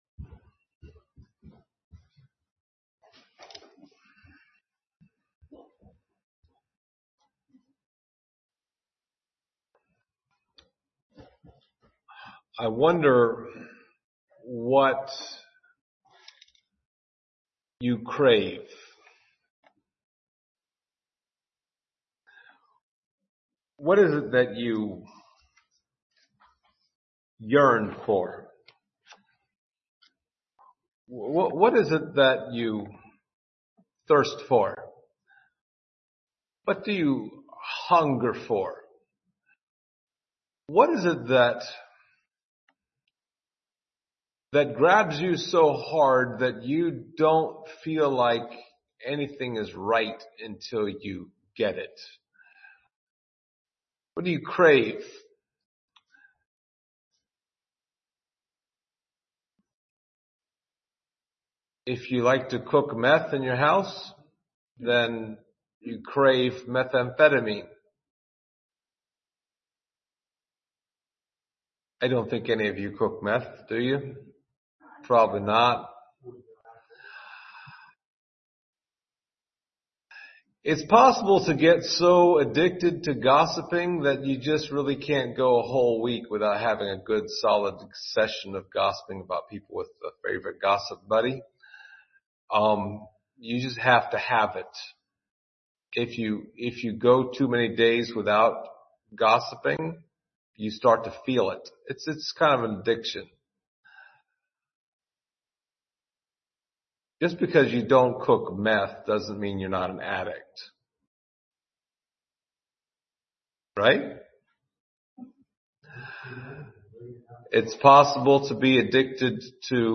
Summer Psalms Passage: Psalm 63 Service Type: Sunday Morning Topics